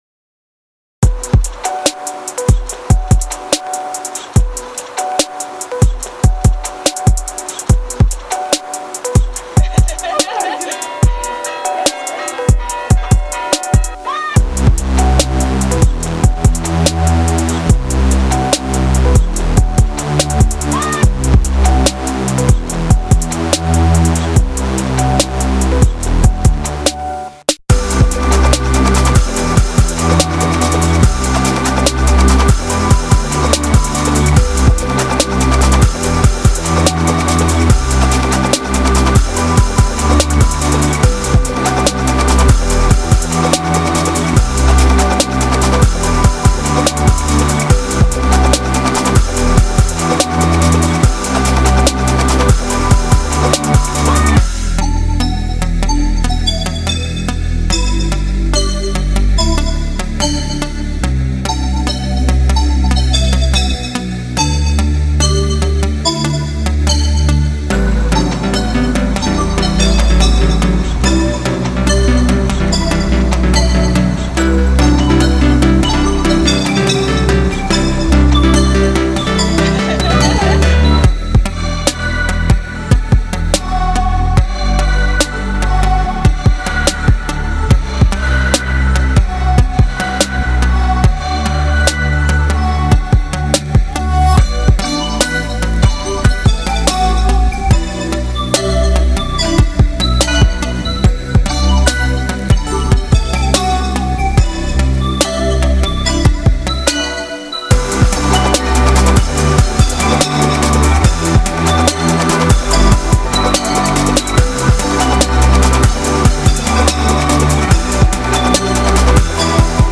All very bright, booty-shaking, catchy, poptastical cuts.